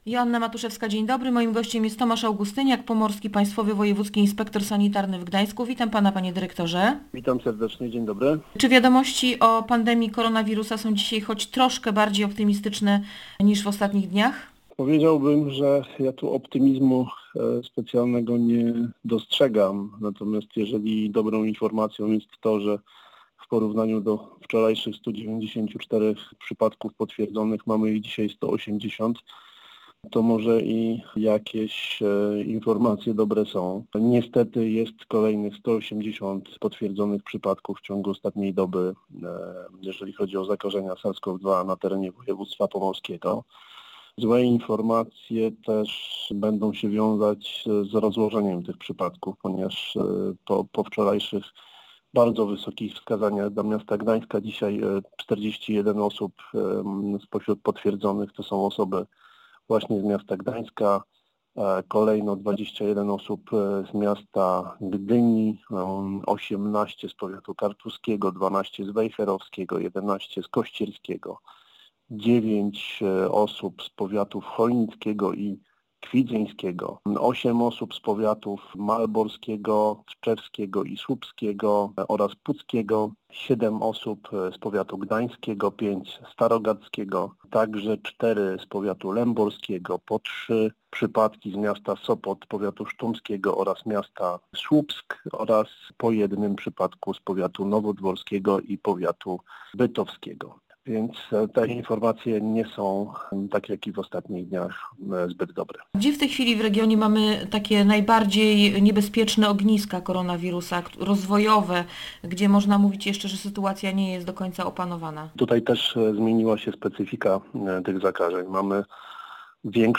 Zaczyna brakować miejsc dla osób, których stan zdrowia się pogorszył- mówił w Radiu Gdańsk Tomasz Augustyniak, Pomorski Państwowy Wojewódzki Inspektor Sanitarny w Gdańsku.